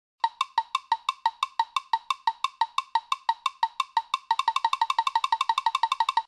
Powerup.ogg